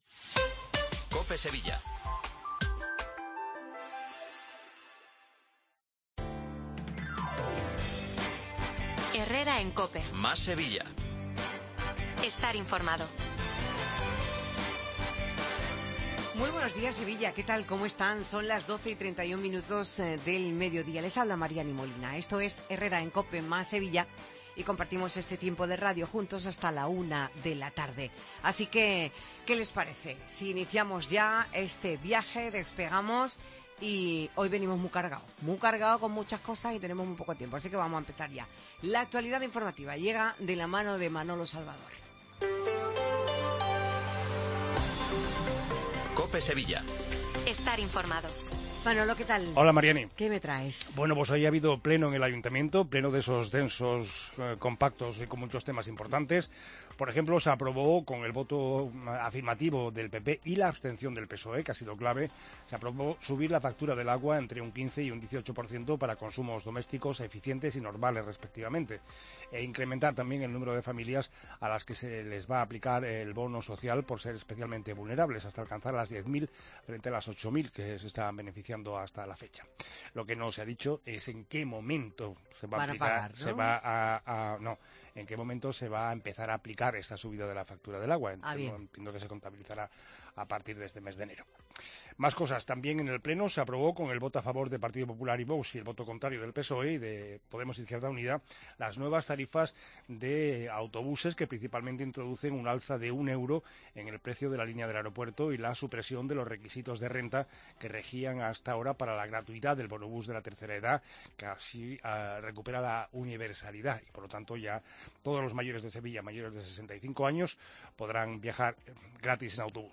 Programa magazine